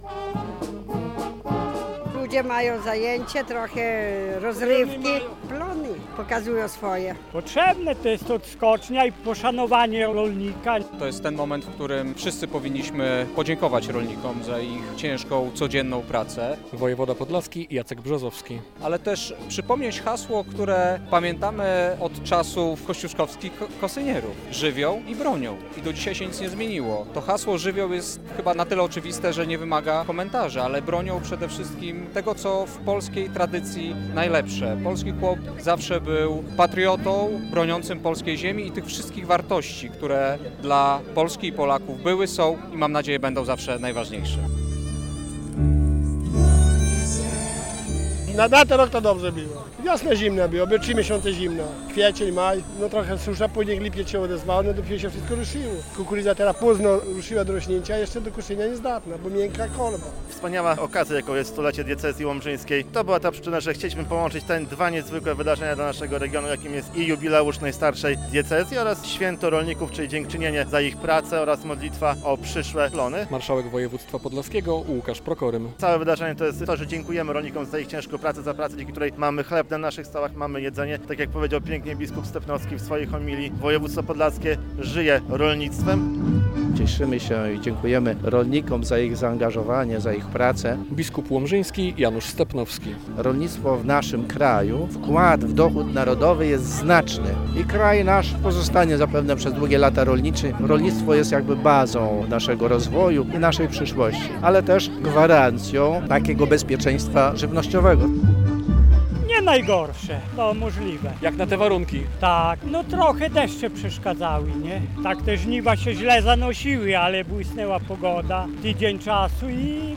Dożynki Wojewódzkie w Małym Płocku - relacja